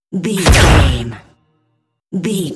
Dramatic hit laser shot
Sound Effects
Atonal
heavy
intense
dark
aggressive